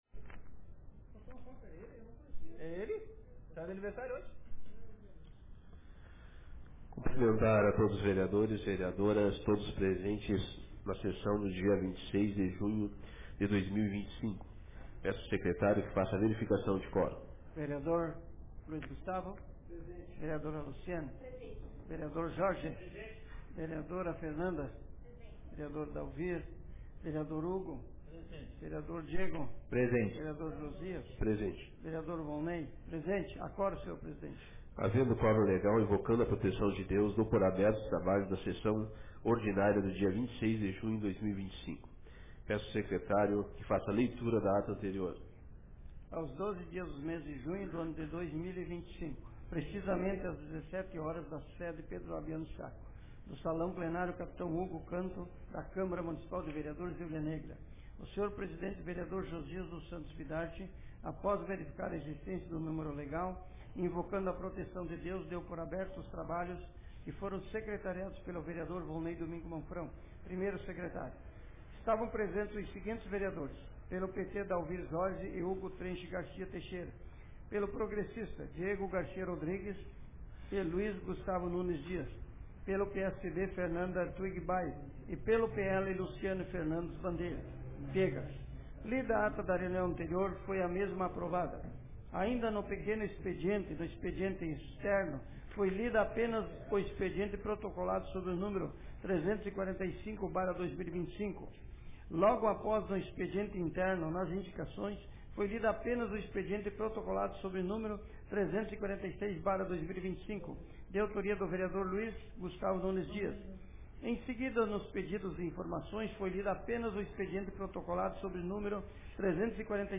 Sessão Ordinária da Câmara de Vereadores de Hulha Negra Data: 26 de junho de 2025